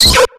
JIGGLYPUFF.ogg